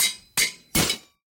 anvil_break.ogg